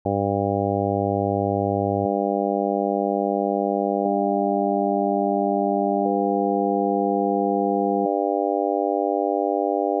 Ниже приведен пример одной синусоиды с различными гармоническими частотами, которые впоследствии добавляются или убираются. Первые несколько секунд содержат основную частоту на 100 Гц, но после этого она отсутствует.
В то время как тембр или фактура самого звука меняется, воспринимаемая нами высота тона не меняется, даже если основная тональность (100 Гц) полностью удалена.